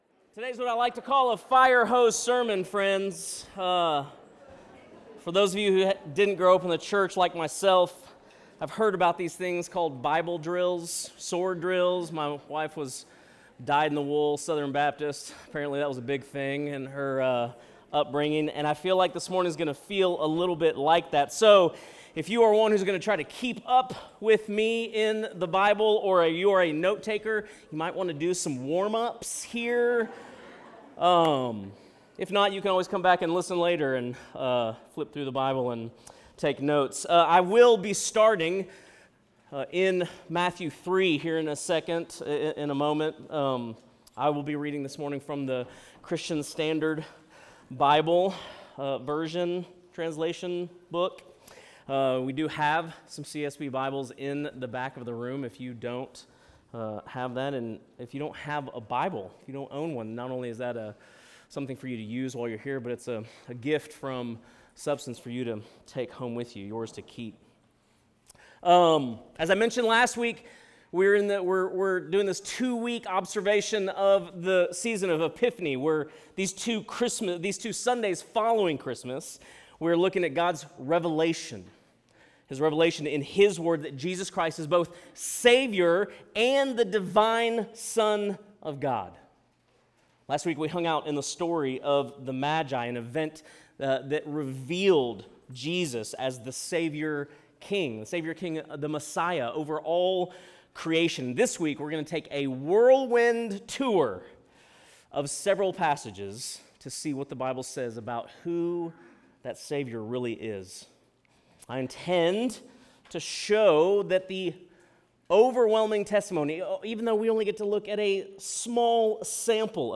Sunday Worship | Substance Church, Ashland, Ohio
Sermons